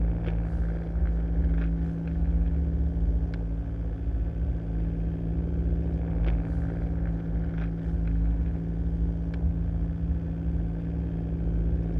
pgs/Assets/Audio/Sci-Fi Sounds/Hum and Ambience/Hum Loop 5.wav at 7452e70b8c5ad2f7daae623e1a952eb18c9caab4
Hum Loop 5.wav